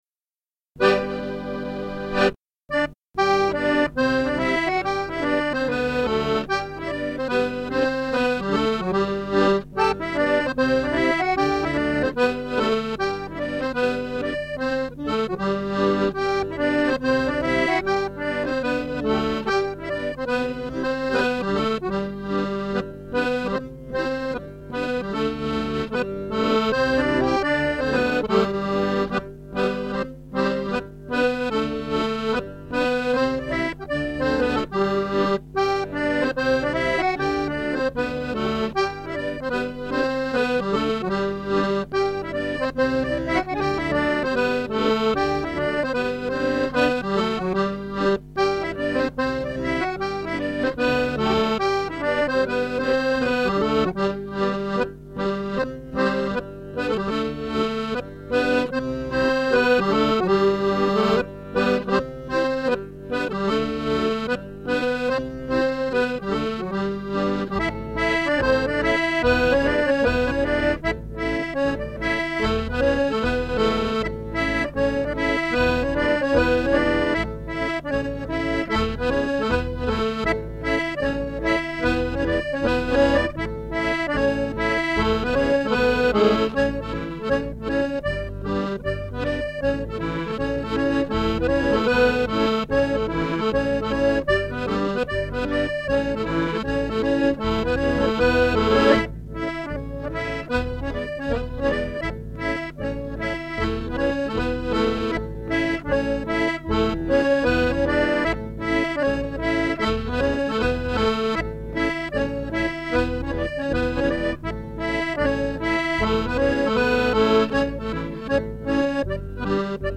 Music - 40 bar hornpipes or jigs
audio/2 x 40 jig + 2 x 4 hornpipe.mp3